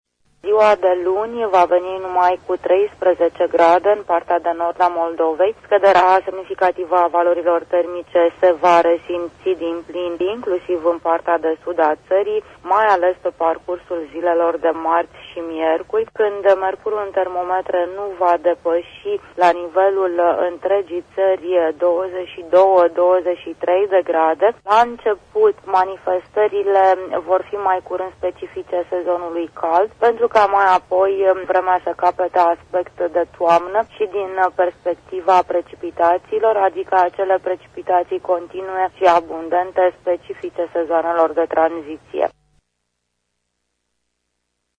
Meteorologul